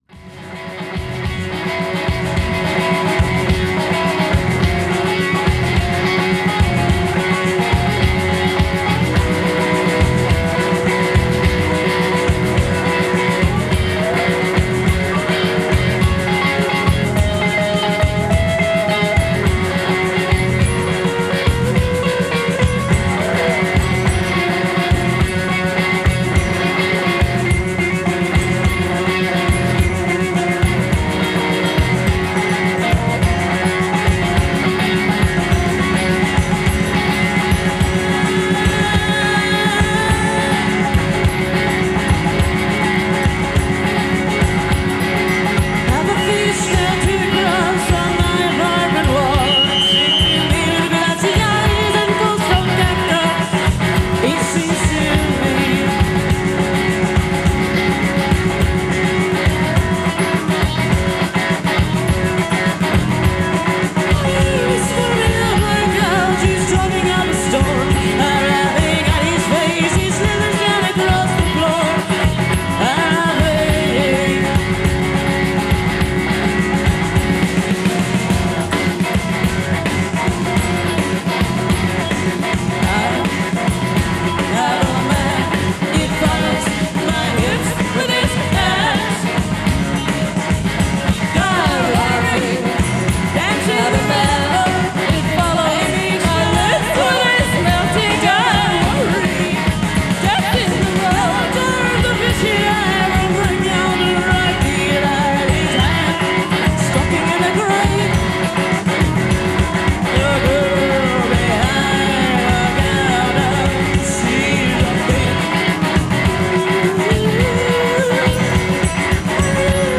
recorded April 14, 1988 - Melkweg, Amsterdam.
recorded at Melkweg in Amsterdam on April 14, 1988